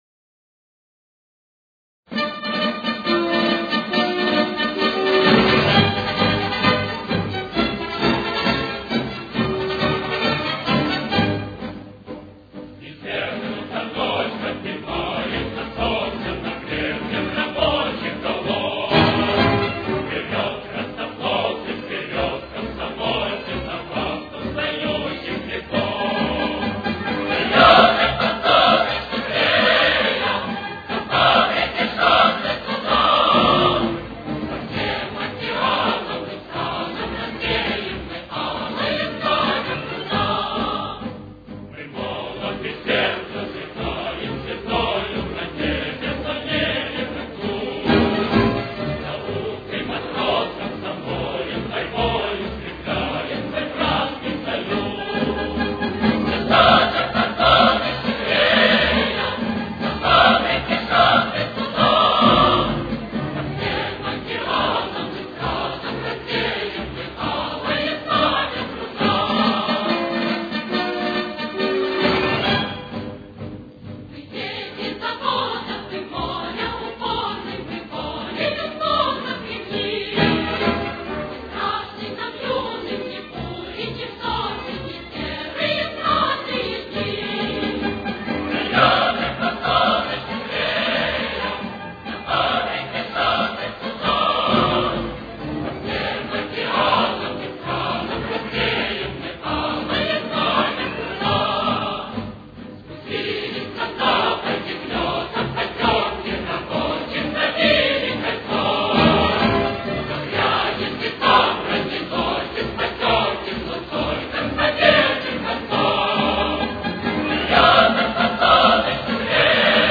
с очень низким качеством (16 – 32 кБит/с)
Темп: 136.